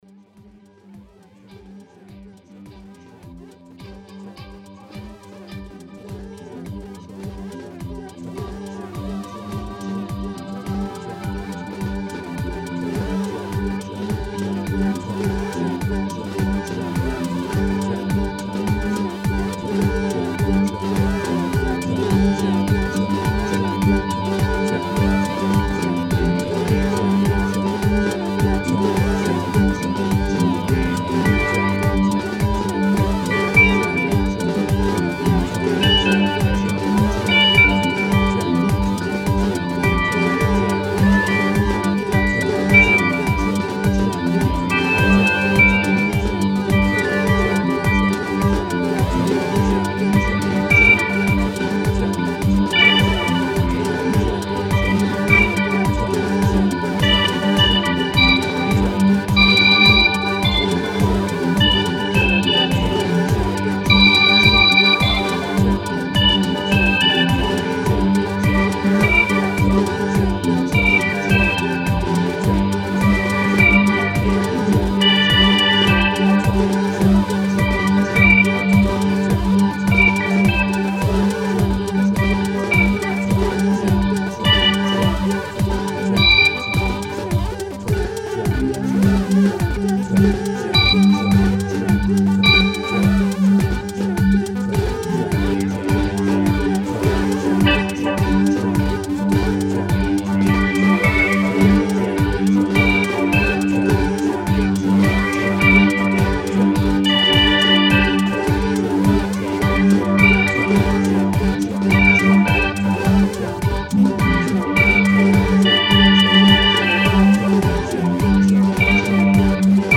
Tempo: 105 bpm / Datum: 17.08.2015